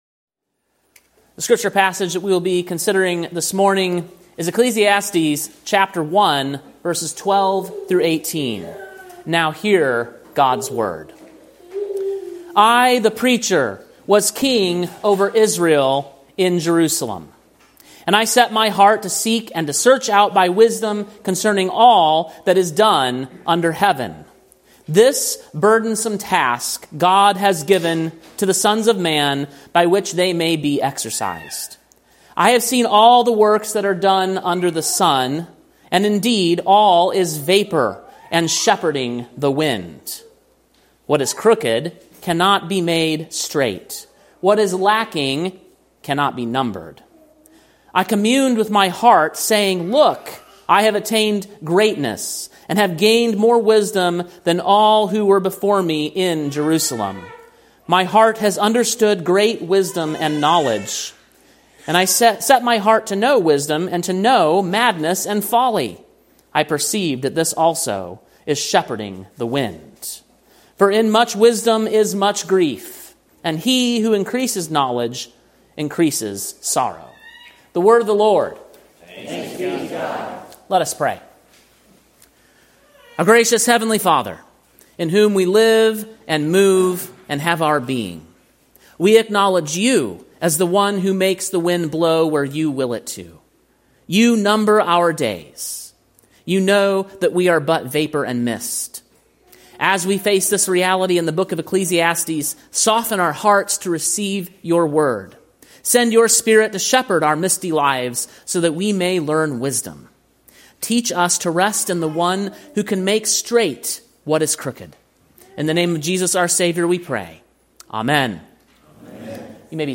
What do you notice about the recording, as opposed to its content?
Sermon preached on August 24, 2025, at King’s Cross Reformed, Columbia, TN.